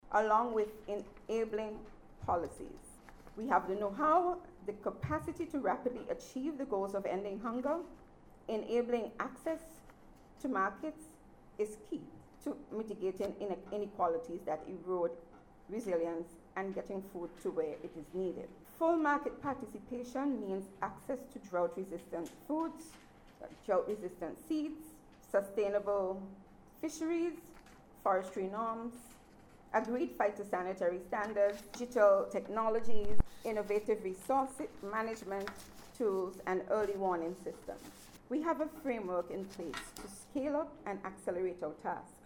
was speaking at the recent launch of activities for World Food Day